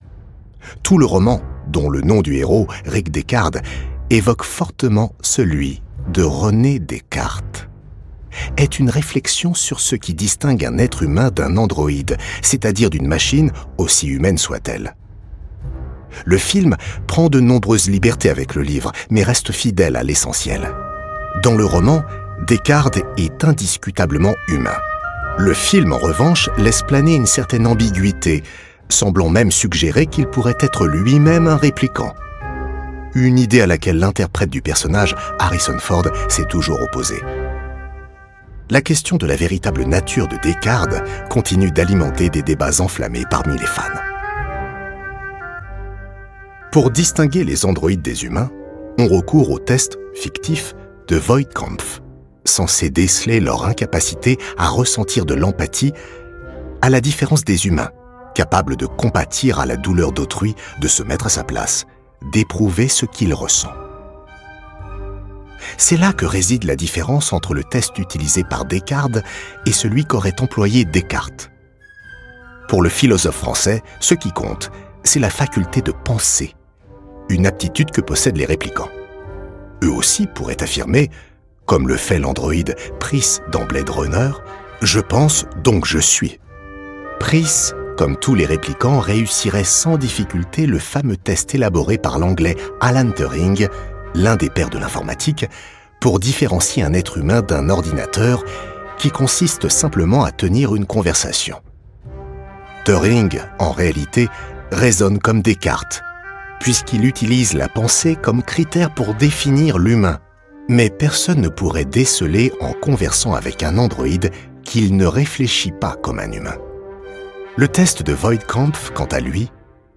Posé et philosophe.
Lecture dans un style didactique, posé et en reflexion.
Enregistré chez Safe and Sound.